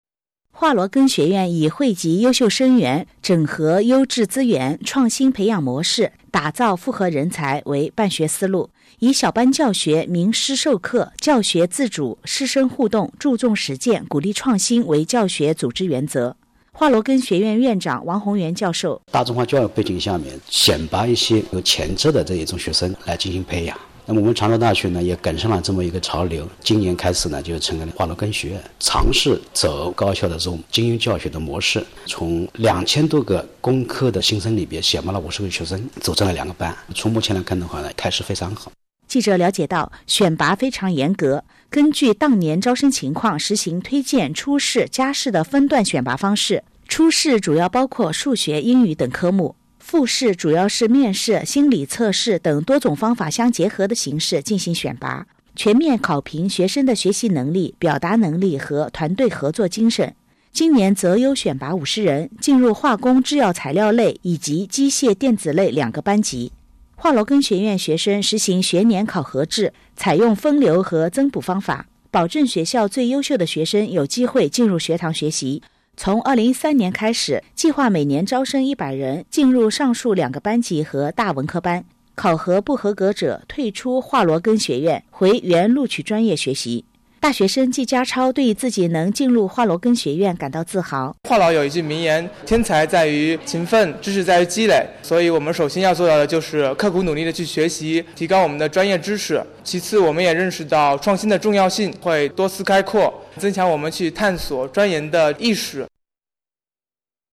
常州广播电台报道我校成立华罗庚学院